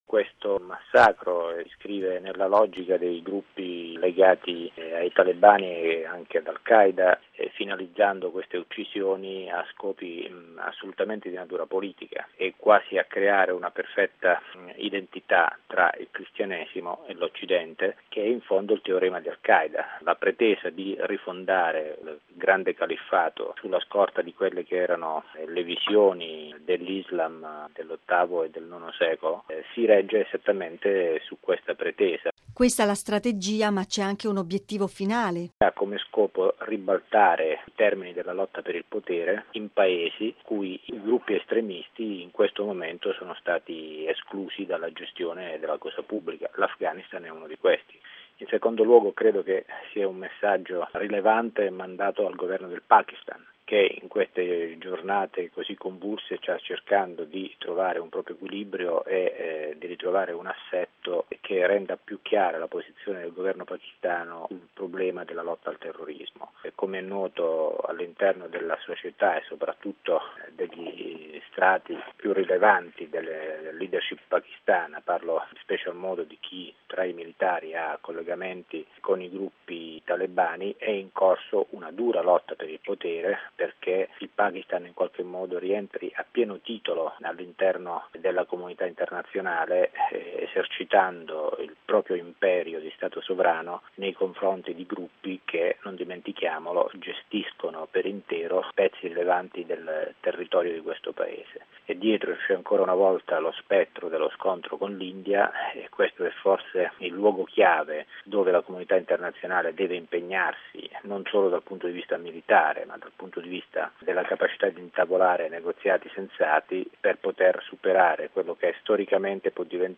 Violenze anticristiane nel mondo: intervista con Mario Mauro